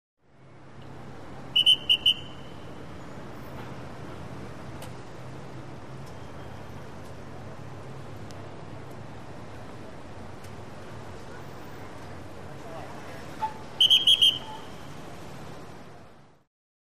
EXT Various|Traffic Cops
Traffic Cop Whistle Close, Two Short Blows; Directing Traffic. Light Traffic Ambience.